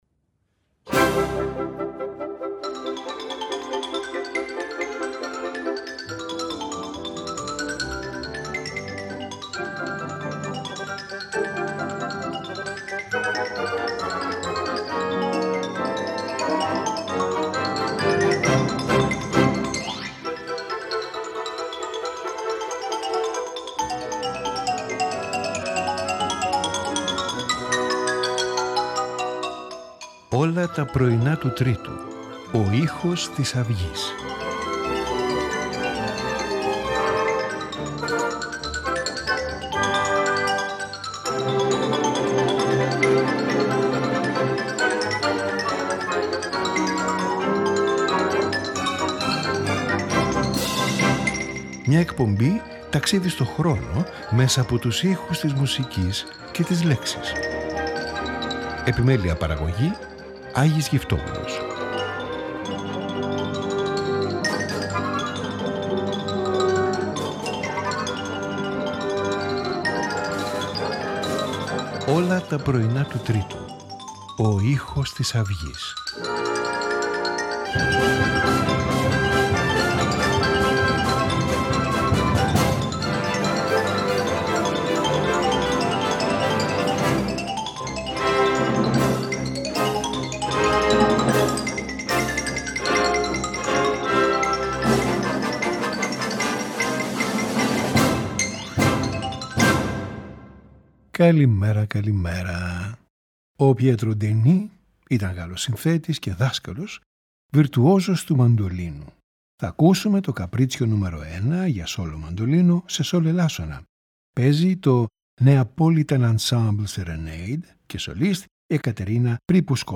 for Solo Mandolin